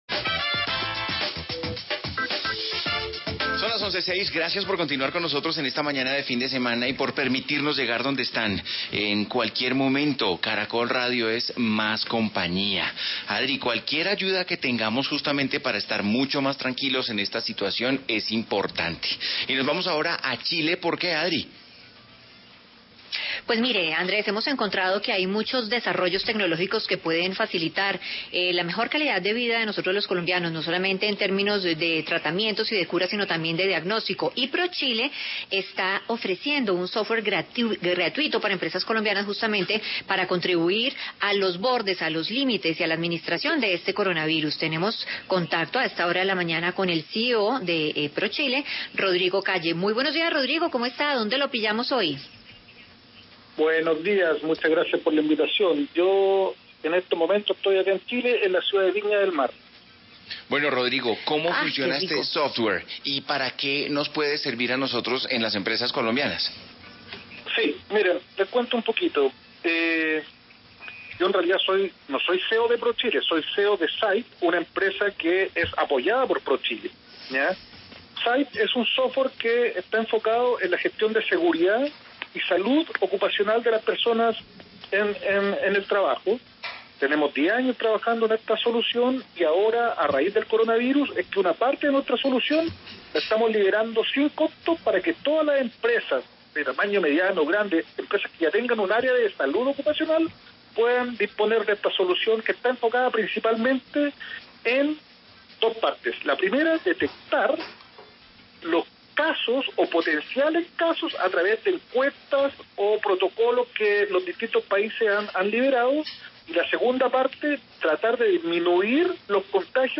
Entrevista ¿Cómo minimizar los riesgos de Coronavirus al interior de las empresas? Radio Caracol, Colombia